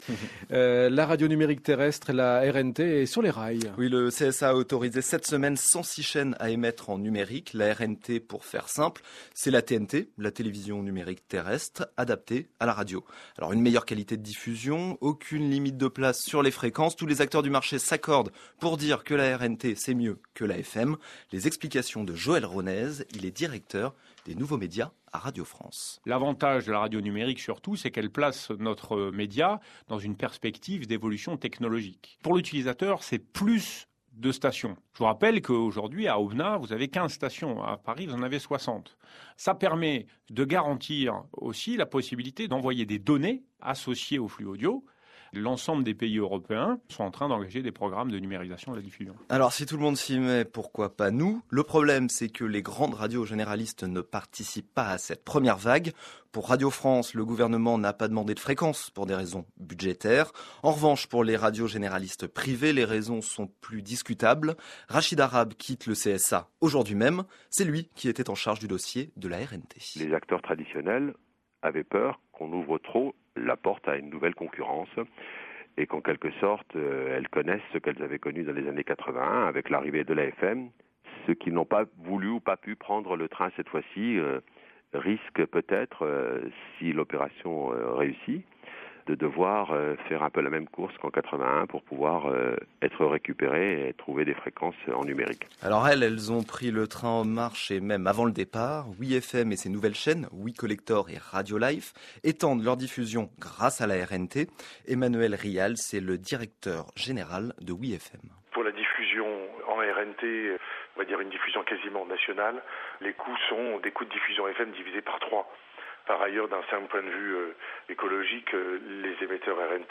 Ce matin à 6h50, la chronique Sans Déconnecter a donc fait l’éloge de la RNT en s’appuyant sur quelques réactions de professionnels
Sans Déconnecter Ecoutez l’ensemble de la chronique et les réactions France_Inter_RNT.mp3 (1.56 Mo)